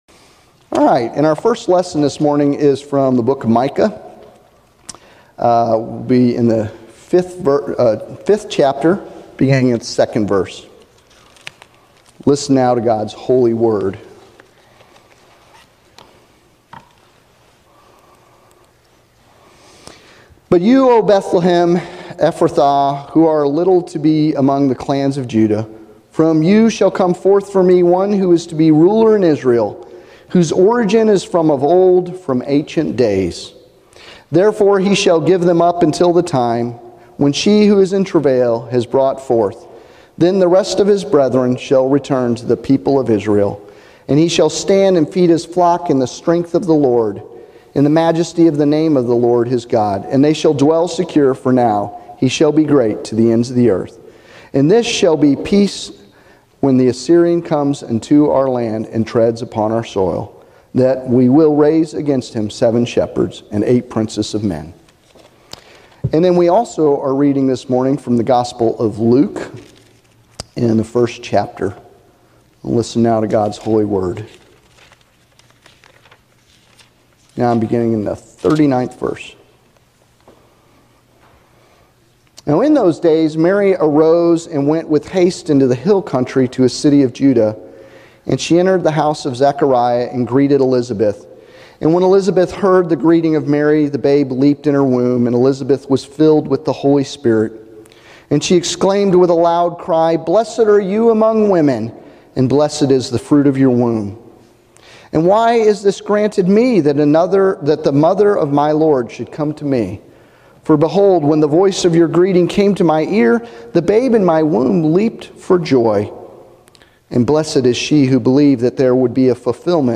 Text for this sermon is Micah 5:2-5 and Luke 1:39-55